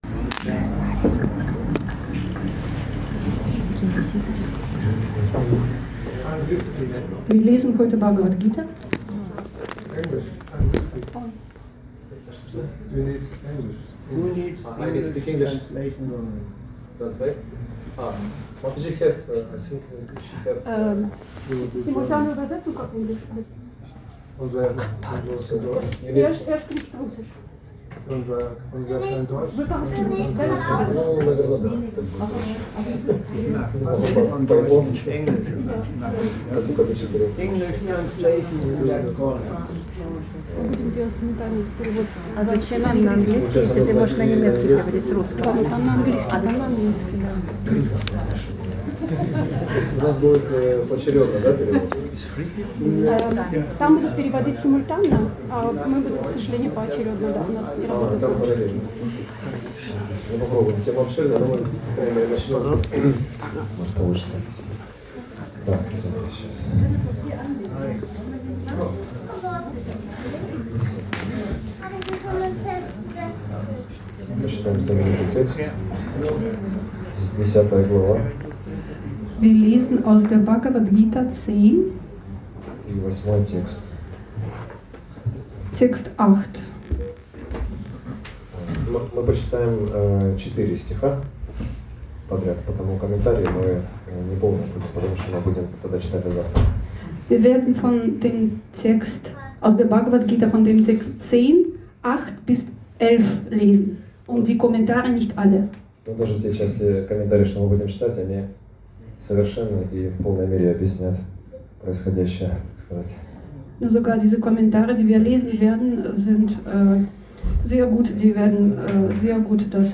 Beschreibung vor 11 Monaten Eine Gruppe junger, inspirierter Devotees besuchte uns im Bhakti Yoga Zentrum Hamburg.